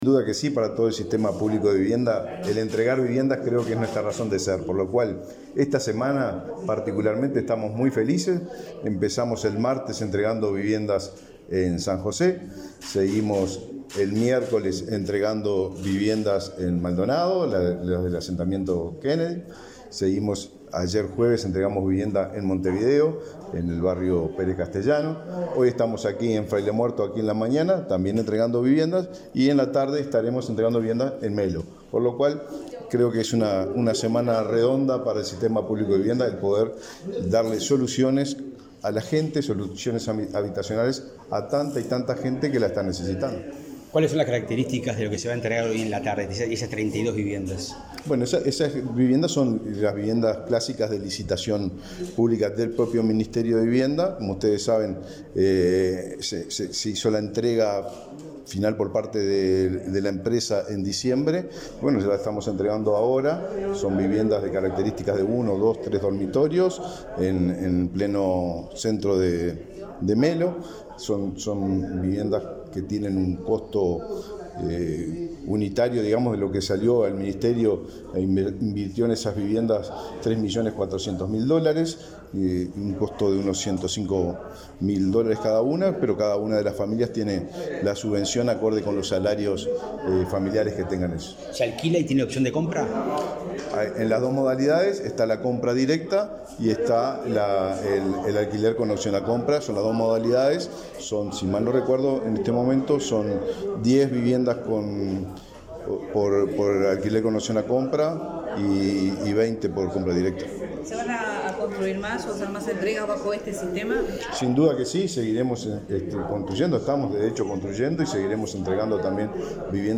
Declaraciones del ministro de Vivienda, Raúl Lozano
Declaraciones del ministro de Vivienda, Raúl Lozano 23/02/2024 Compartir Facebook X Copiar enlace WhatsApp LinkedIn El ministro de Vivienda, Raúl Lozano, dialogó con la prensa, antes de participar en el acto de inauguración de 54 viviendas de Mevir en la localidad de Fraile Muerto, Cerro Largo. En la oportunidad, se refirió a la entrega de soluciones habitaciones en Melo en la tarde de este viernes 23.